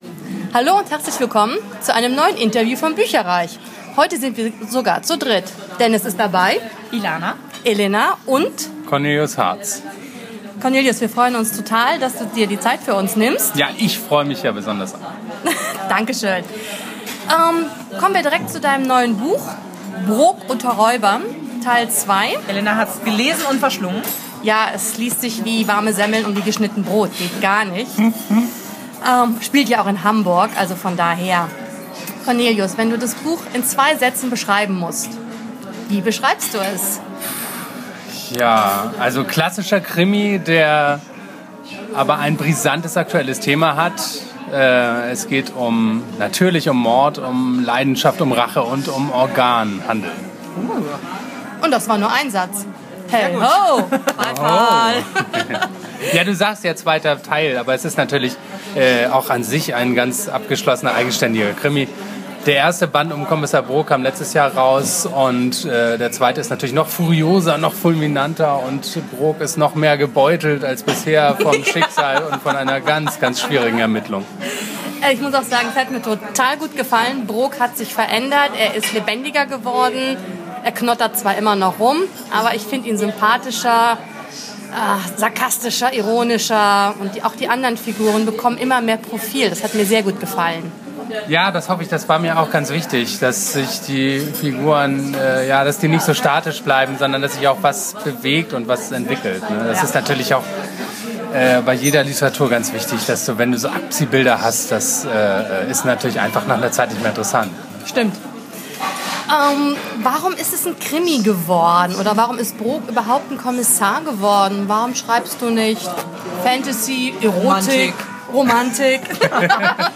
Heute haben wir wieder ein Interview für euch!